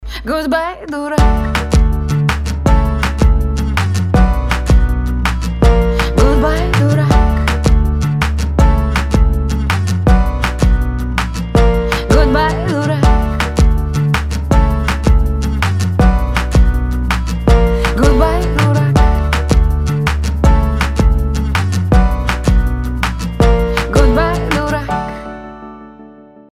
• Качество: 320, Stereo
гитара
женский вокал
забавные